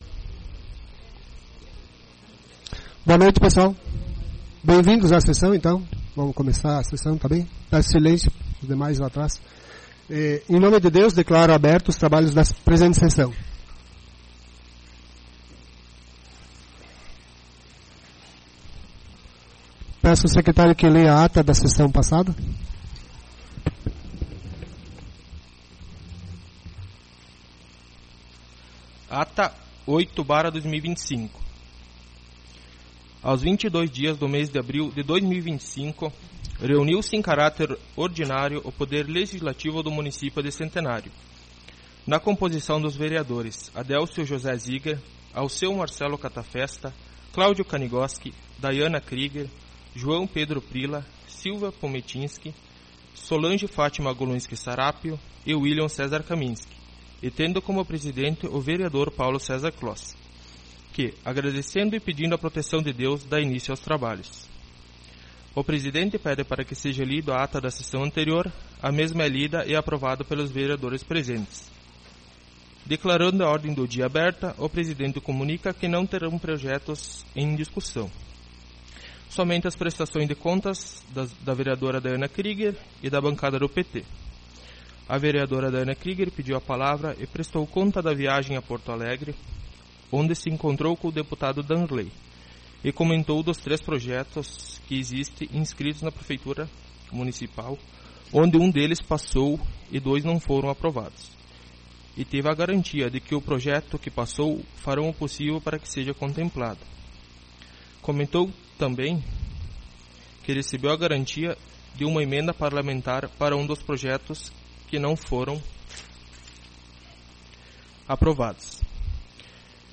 Sessão Ordinária 05/05/2025